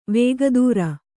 ♪ vēga dūra